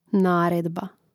náredba naredba